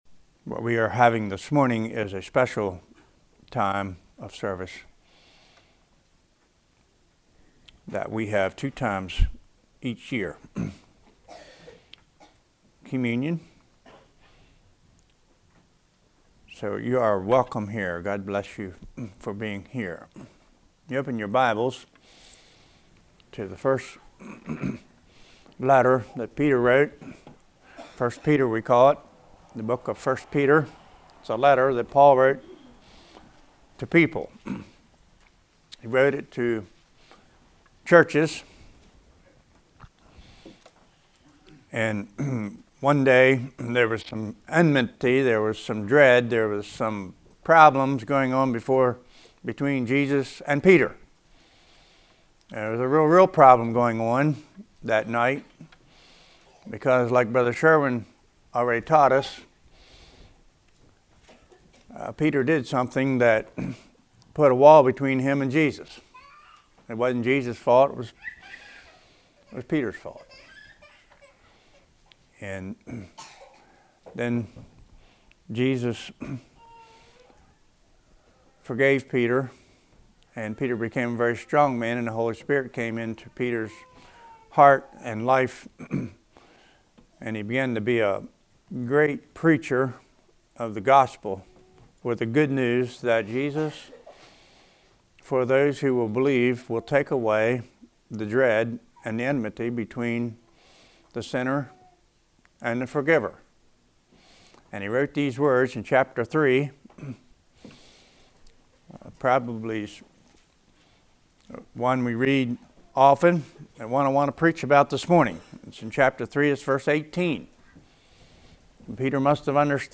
Congregation: Winchester